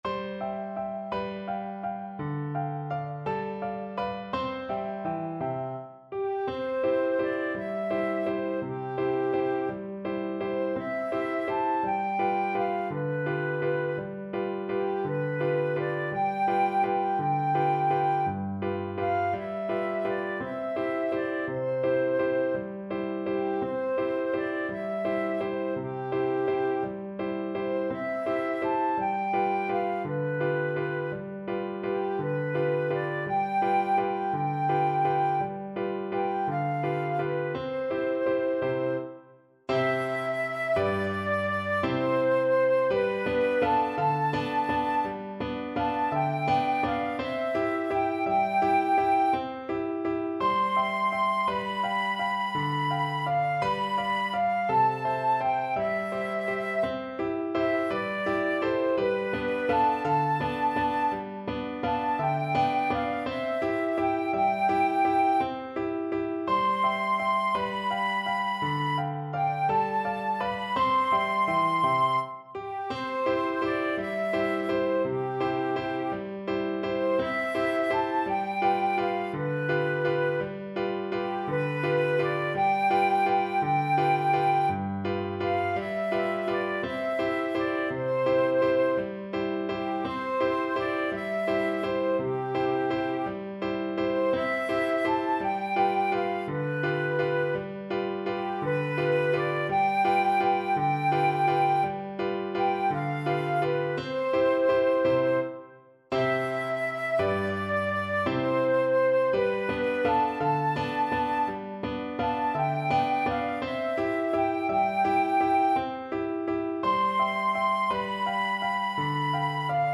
Flute
C major (Sounding Pitch) (View more C major Music for Flute )
One in a bar =c.168
3/4 (View more 3/4 Music)
Traditional (View more Traditional Flute Music)